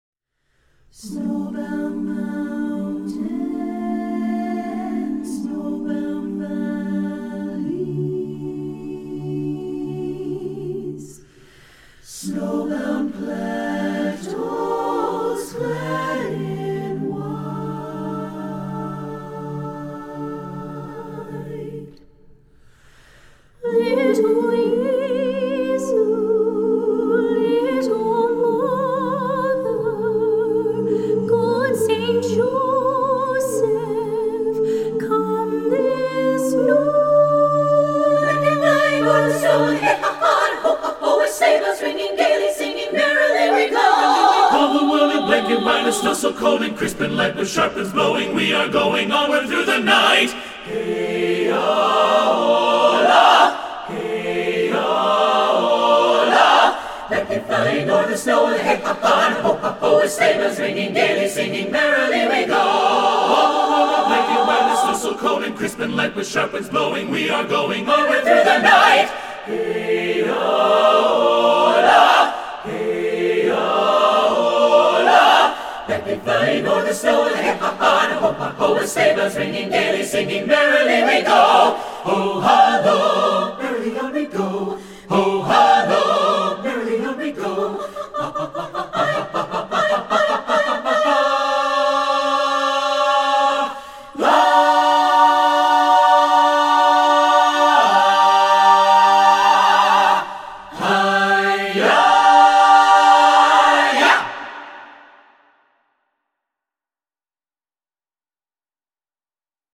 Composer: Russian Carol
Voicing: SATB a cappella